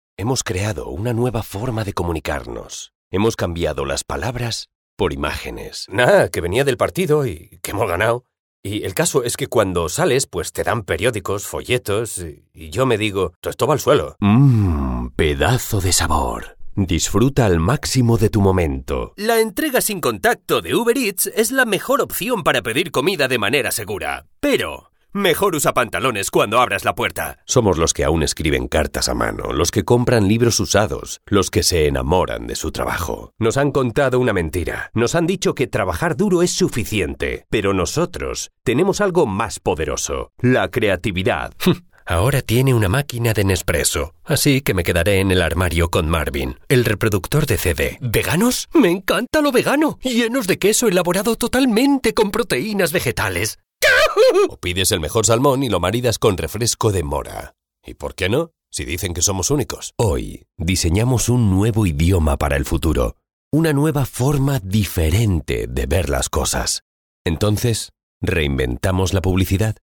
Male
20s, 30s, 40s
Confident, Cool, Corporate, Friendly, Natural, Warm
Castilian (Euro Spanish NATIVE) and Neutral (Latam Spanish 10 YEARS IN MEXICO)
Voice reels
Microphone: Neumann TLM 103
Audio equipment: STUDIOBRICKS SOUNDBOOTH, UNIVERSAL AUDIO APOLLO SOLO